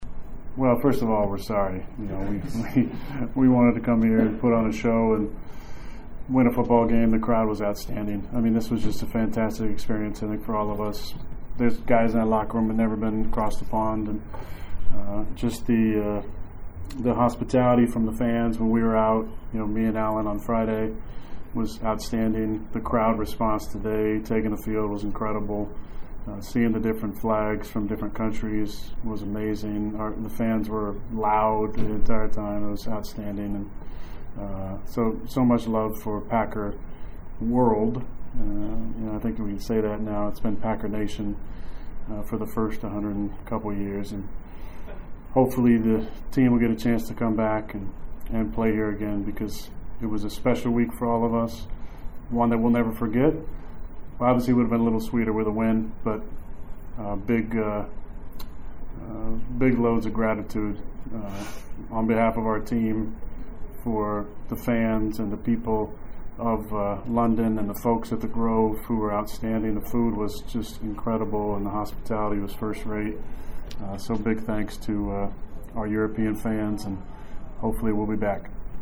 Aaron Rodgers meets the international press.